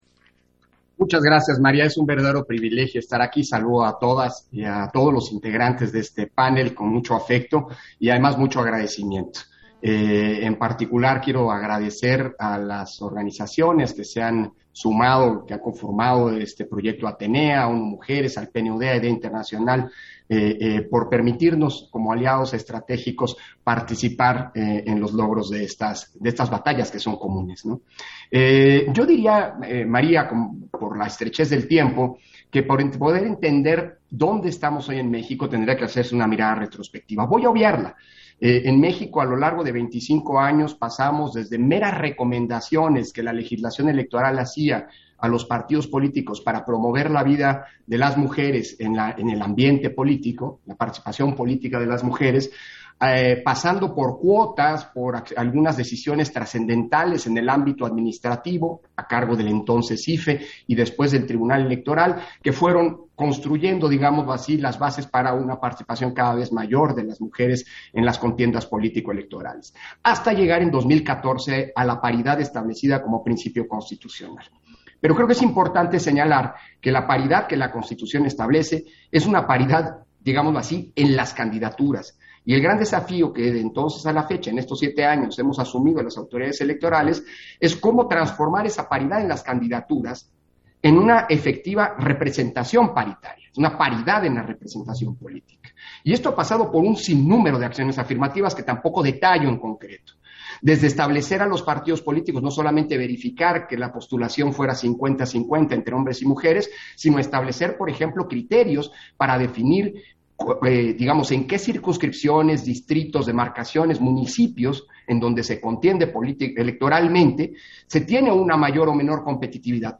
Intervenciones de Lorenzo Córdova, en el seminario regional, Democracias paritarias e Inclusivas: Desafíos en el contexto actual